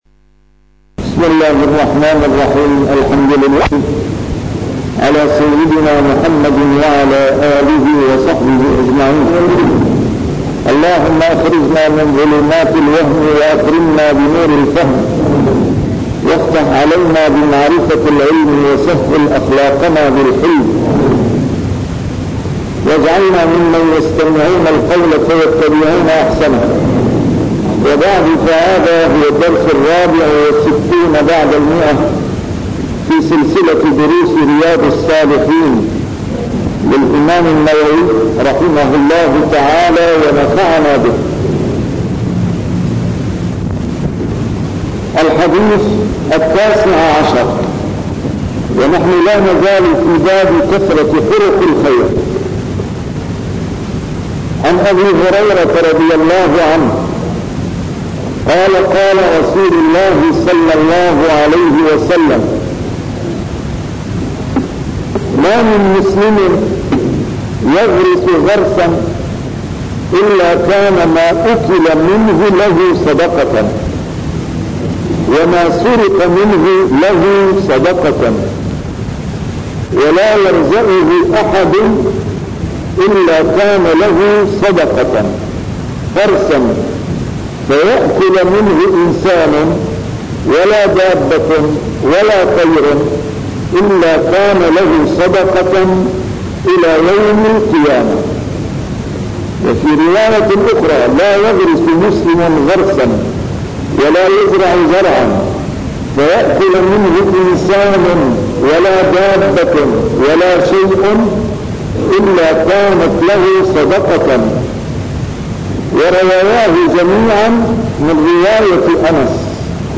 A MARTYR SCHOLAR: IMAM MUHAMMAD SAEED RAMADAN AL-BOUTI - الدروس العلمية - شرح كتاب رياض الصالحين - 164- شرح رياض الصالحين: كثرة طرق الخير